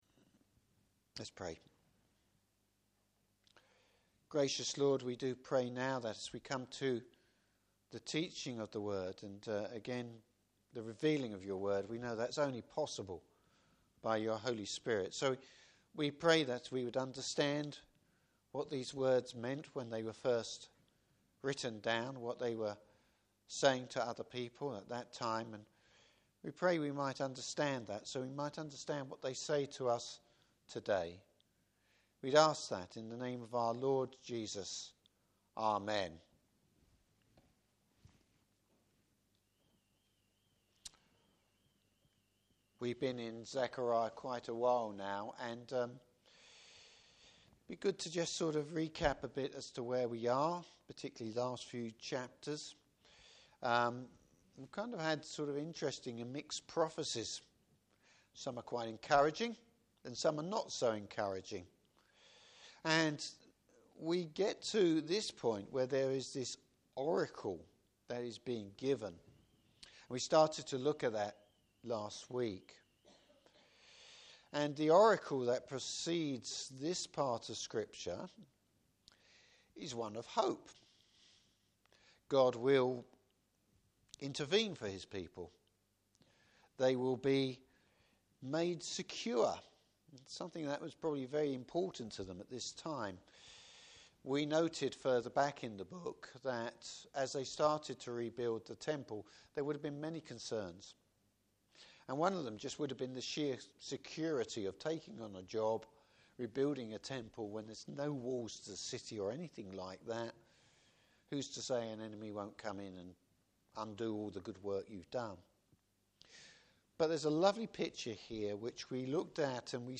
Service Type: Evening Service The repentance of God’s people.